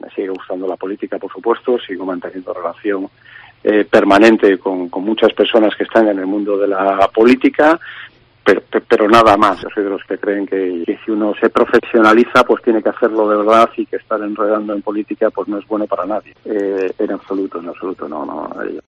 Íñigo De la Serna en declaraciones a COPE Cantabria en 2020